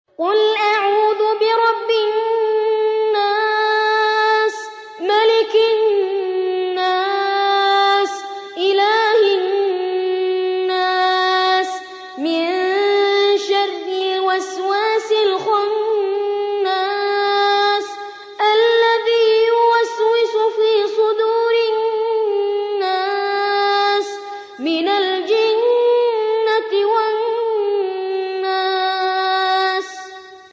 قارئ معتمد رواية ورش عن نافع
أحد أشهر قراء القرآن الكريم في العالم الإسلامي، يتميز بجمال صوته وقوة نفسه وإتقانه للمقامات الموسيقية في التلاوة.